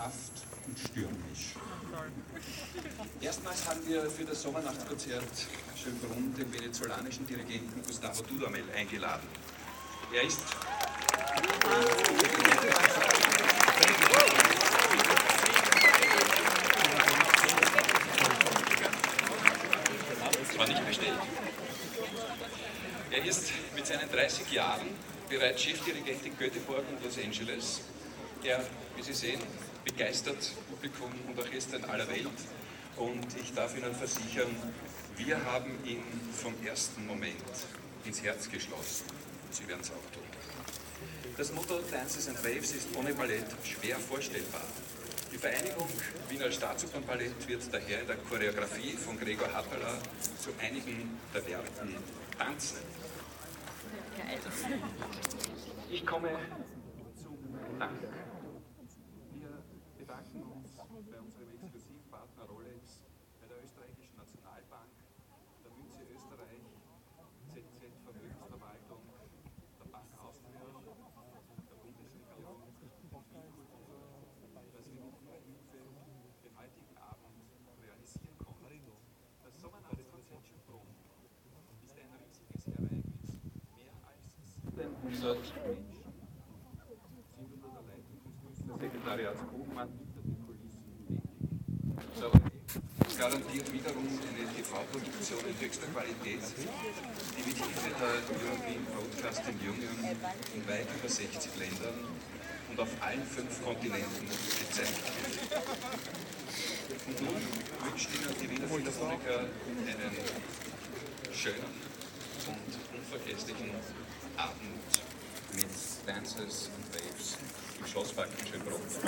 Sommernachtskonzert Ansprache